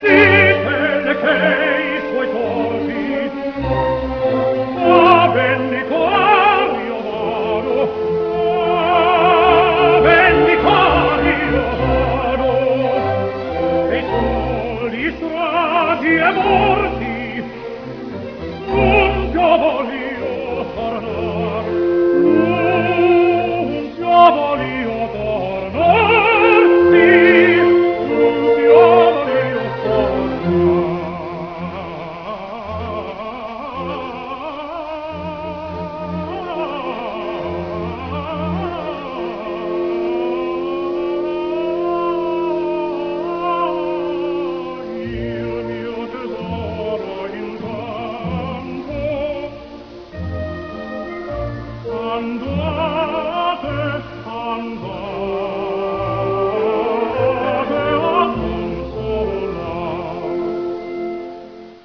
T   E   N   O   R
Full response mono - 5.5 kHz - 1 minute 2 seconds